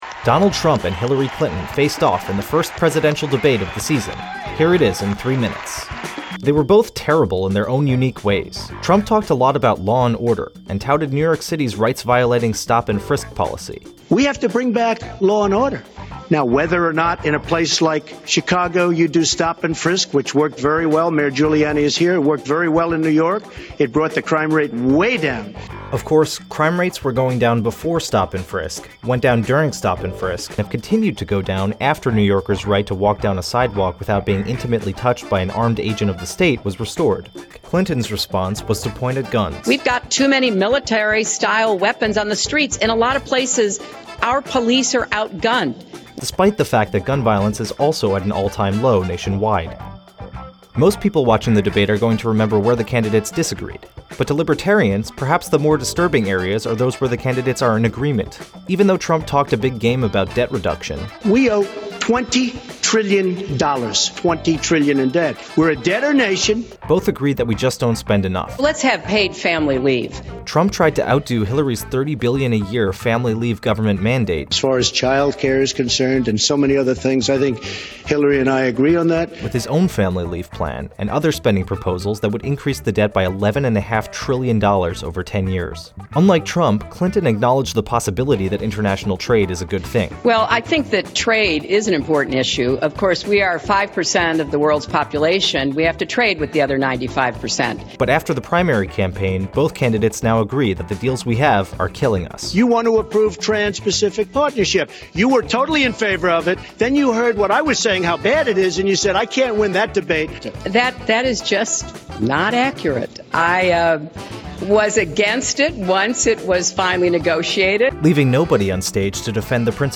Hillary Clinton and Donald Trump face off.
Hillary Clinton and Donald Trump met for the first presidential debate last night at Hofstra University in New York.
Also, NBC's Lester Holt made a brief appearance as moderator.